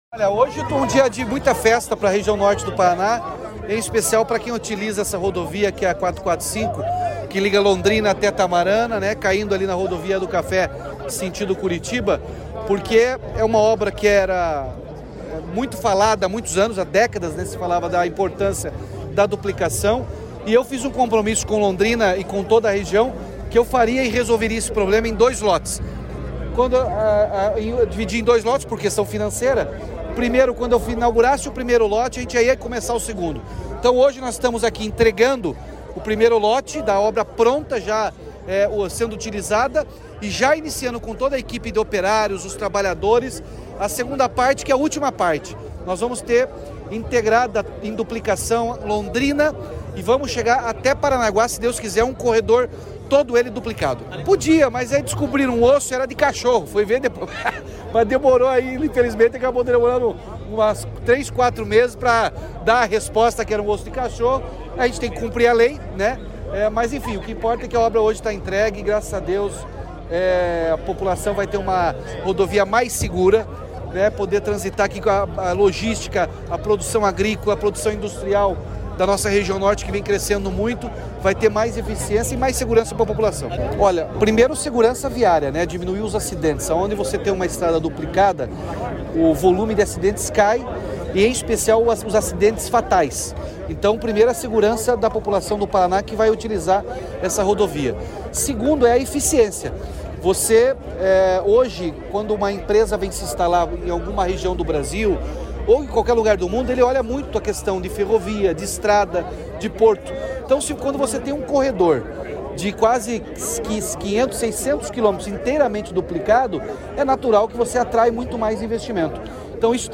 Sonora do governador Ratinho Junior sobre a duplicação da PR-445 de Mauá da Serra a Lerroville